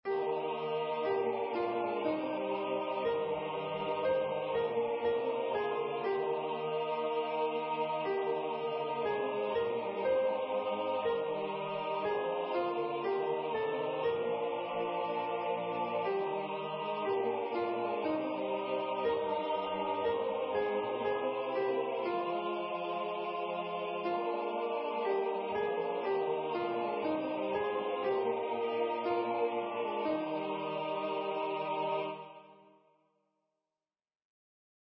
with Accompaniment
MP3 Practice Files: Soprano:
AbideWithMeSoprP.mp3